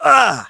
Riheet-Vox_Damage_03.wav